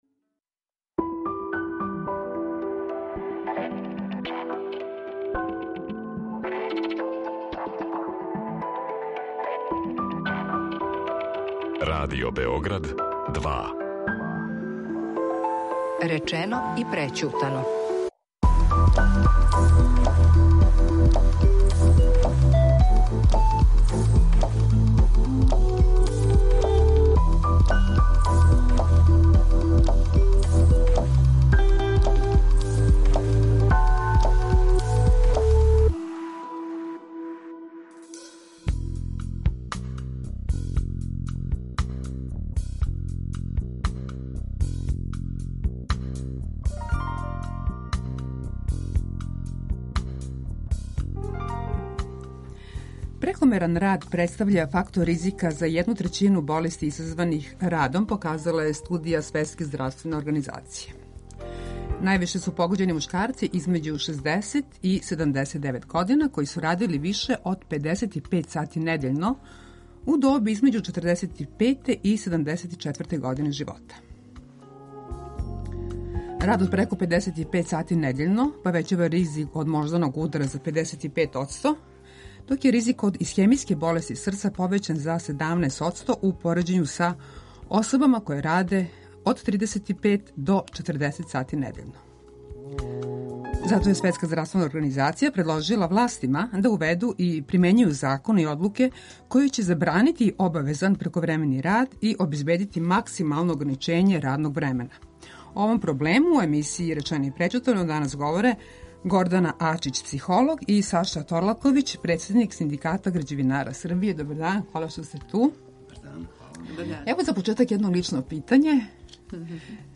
психолог